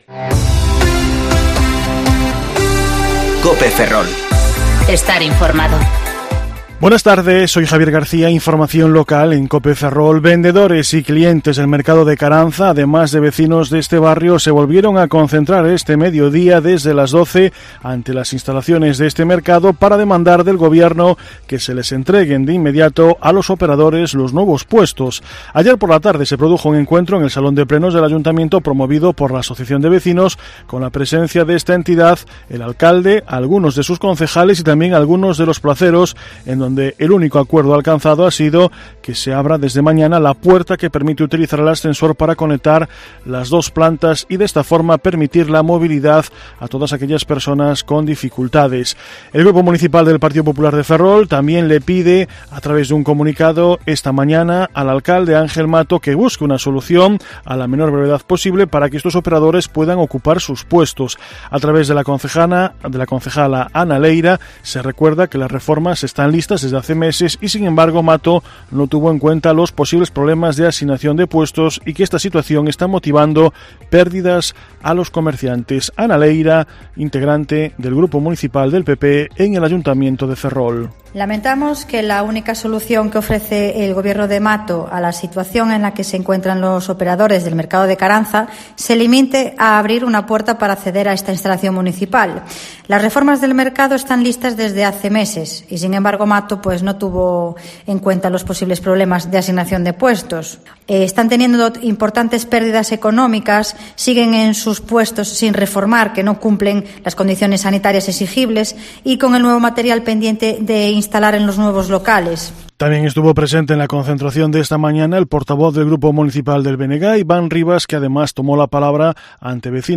Informativo Mediodía COPE Ferrol 7/10/2020 (De 14,20 a 14,30 horas)